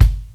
56BRUSHBD -R.wav